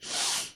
Sniffle.wav